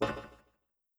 snd_footstep2.wav